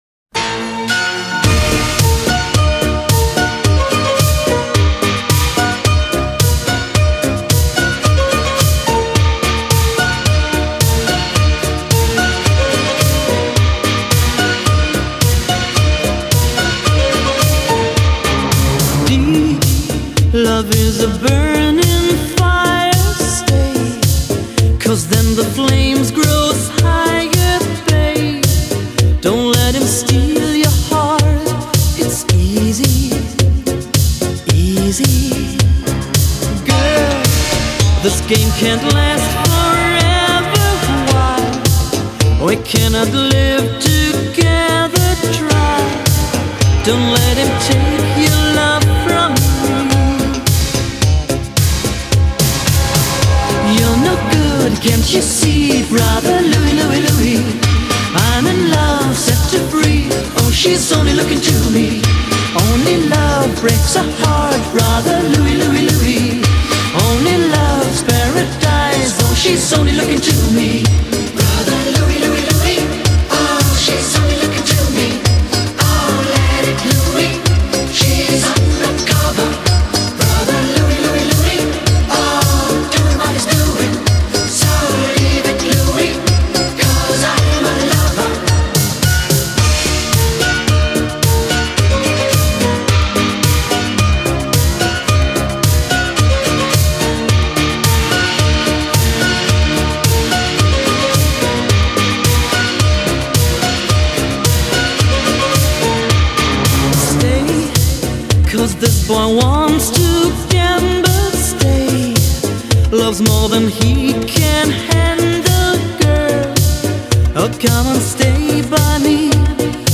Genre: Europop, Dance-pop